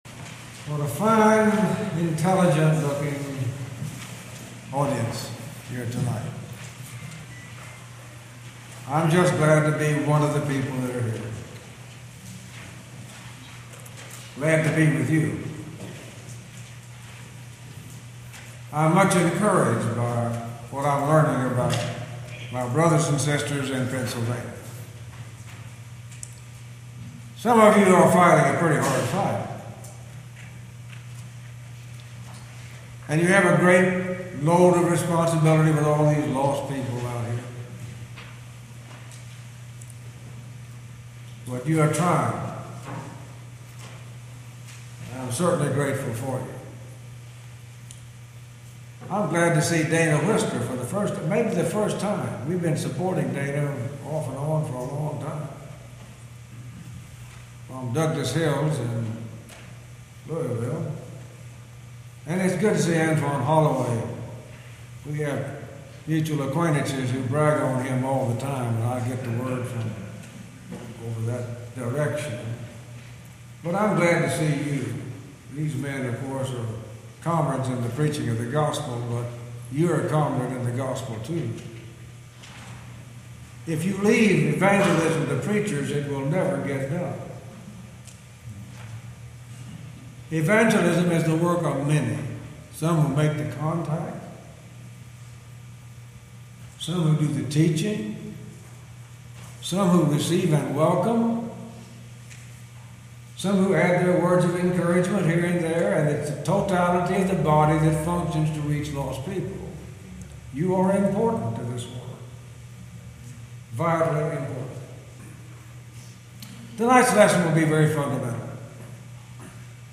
Series: Gettysburg 2013 Gospel Meeting